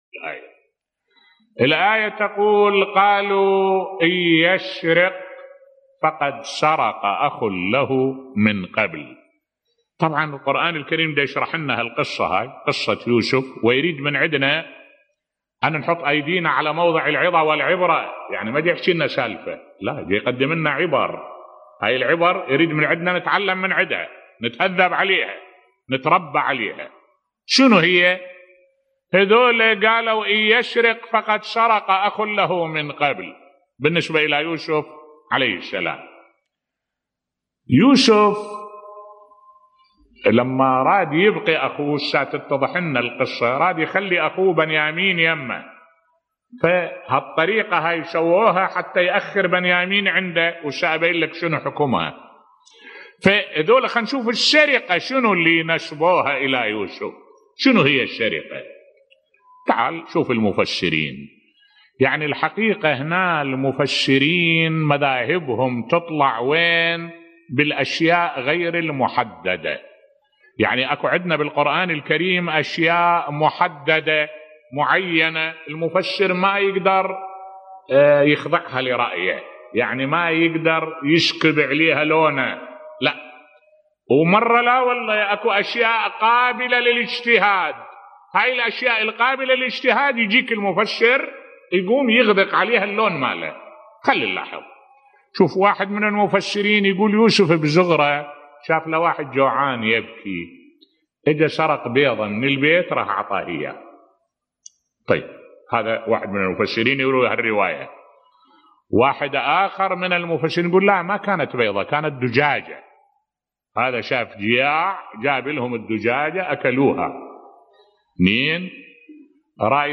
ملف صوتی آراء المفسرين في السرقة التي سرقها النبي يوسف في صغره بصوت الشيخ الدكتور أحمد الوائلي